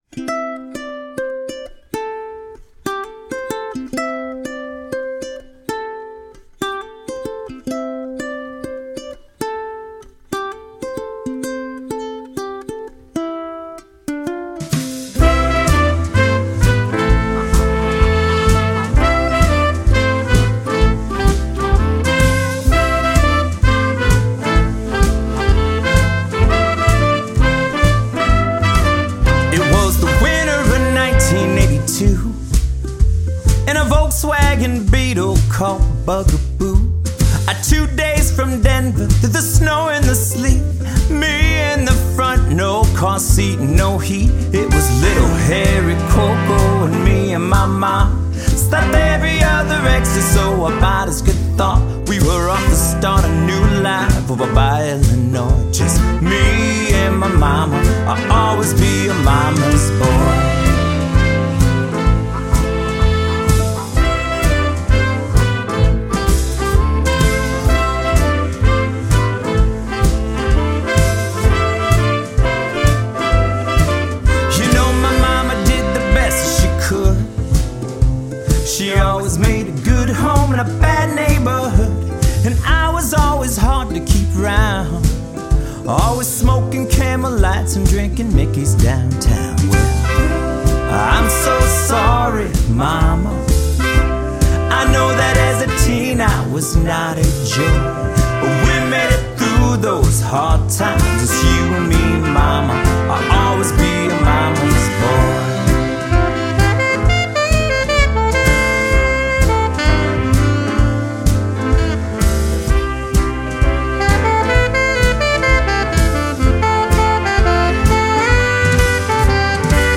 An upbeat Jazzy song about my Mom.